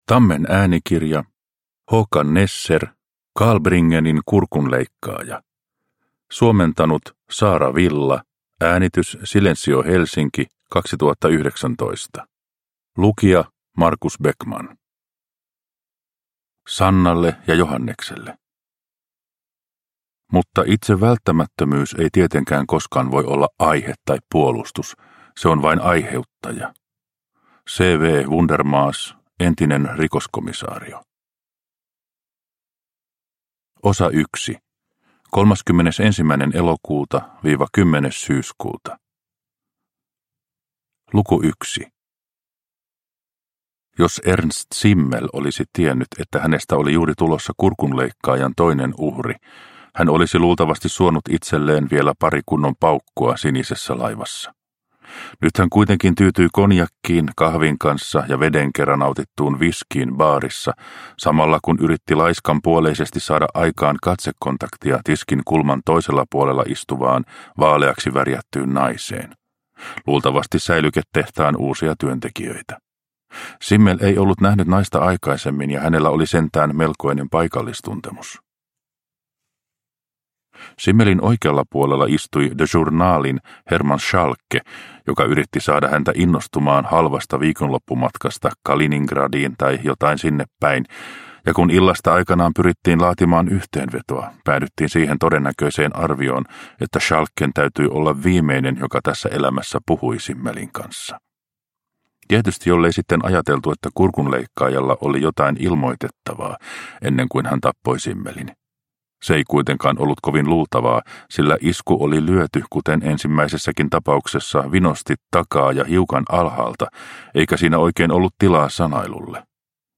Kaalbringenin kurkunleikkaaja – Ljudbok – Laddas ner